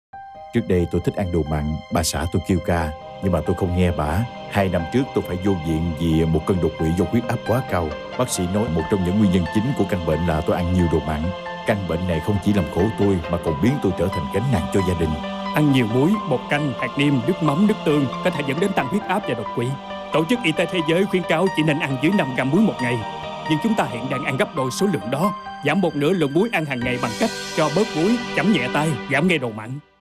radio_spot_nam.wav